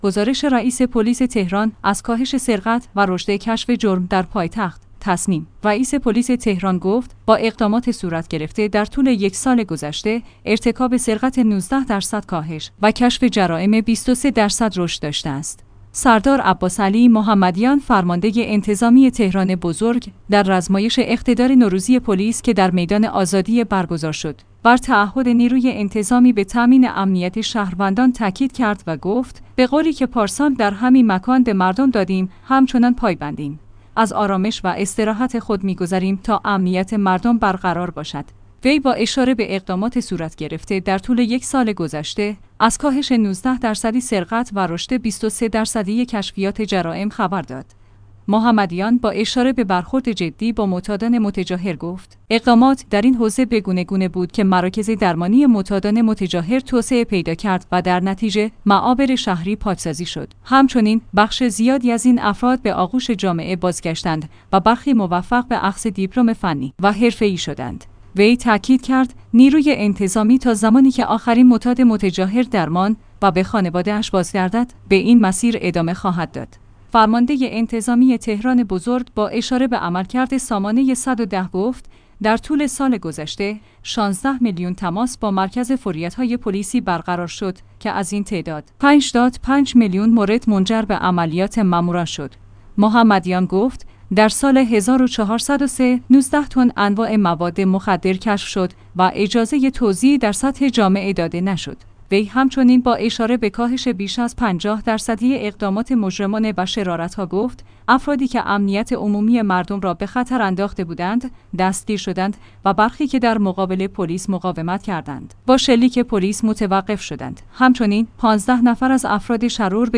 گزارش رئیس پلیس تهران از کاهش سرقت و رشد کشف جرم در پایتخت